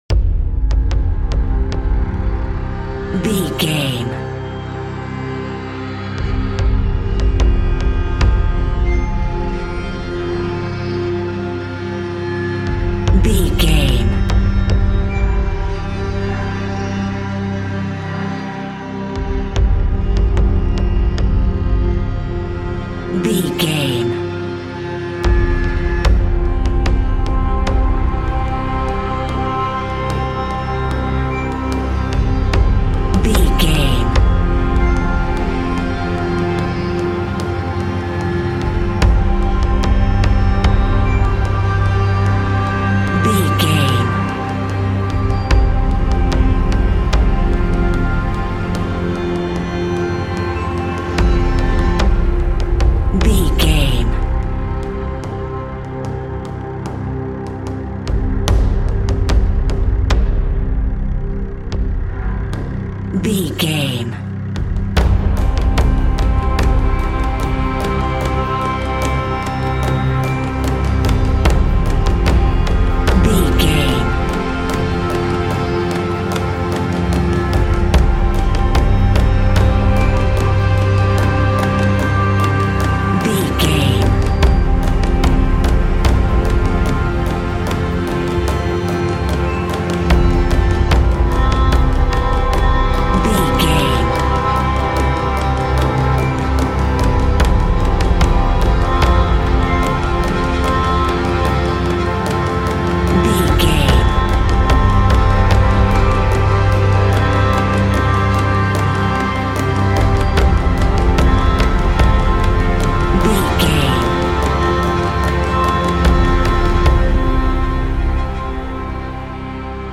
Ionian/Major
cold
dark
eerie
hypnotic
meditative
monochord
new age
taiko